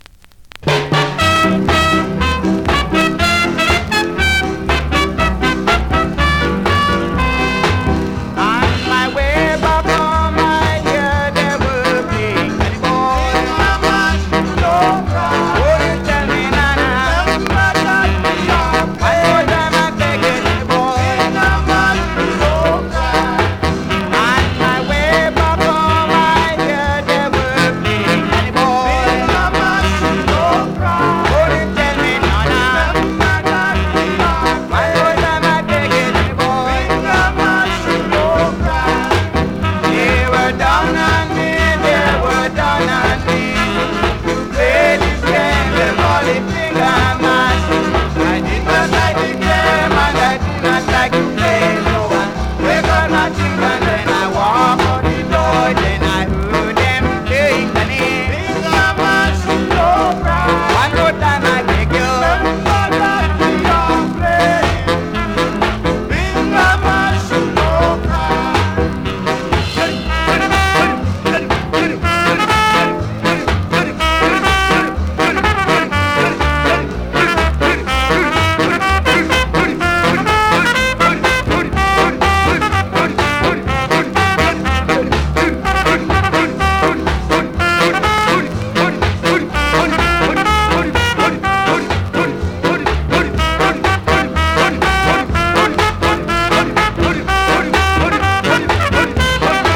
〜VG+ コメントMEGA RARE SKA!!
スリキズ、ノイズ比較的少なめで